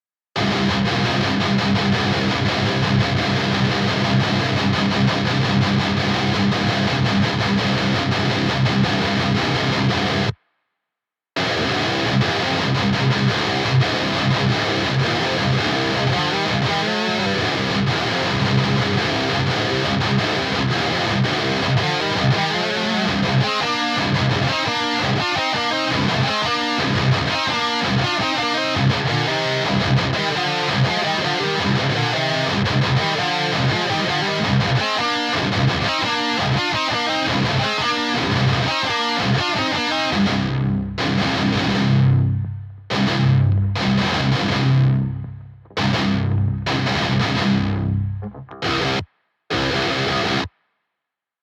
����� ����: Metalcore - ���� ��� �����, ����� �������) (��������� 1679 ���)